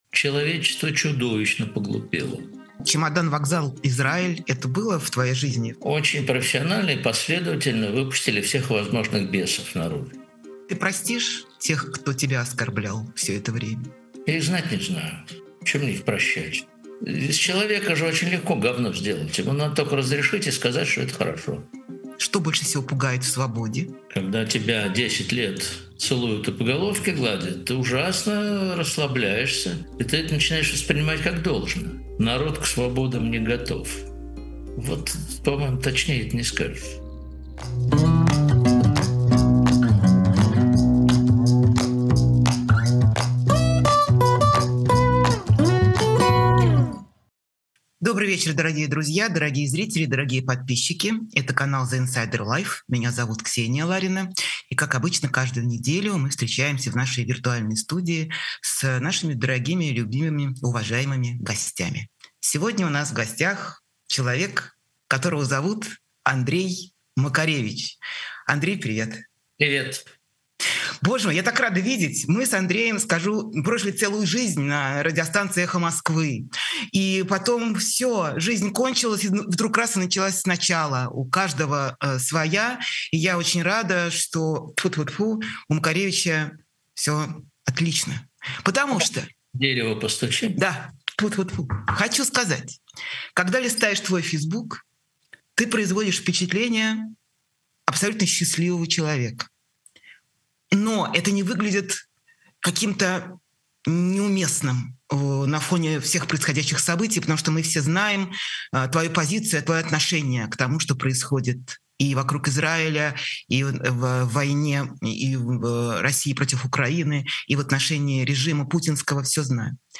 Эфир ведёт Ксения Ларина
Гость — музыкант Андрей Макаревич.